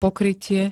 Zvukové nahrávky niektorých slov
zwmv-pokrytie.spx